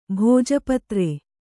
♪ bhōja patre